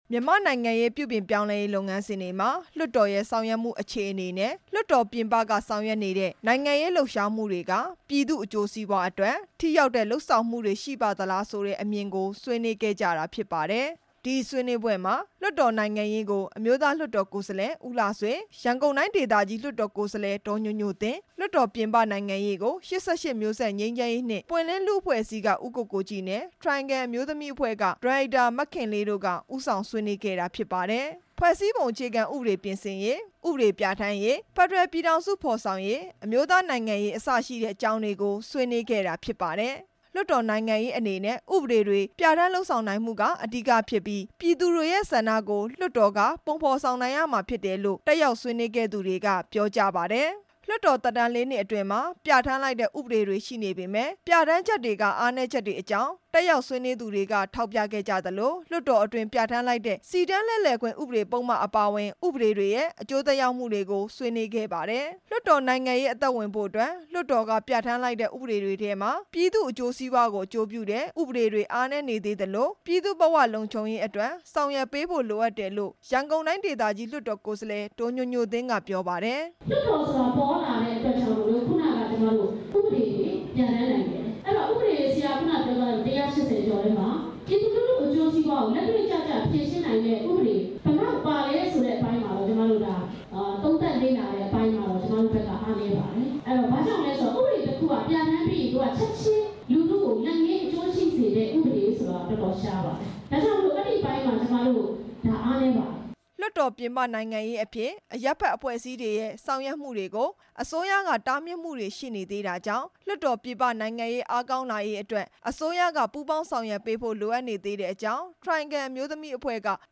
ဆွေးနွေးပွဲအကြောင်း တင်ပြချက်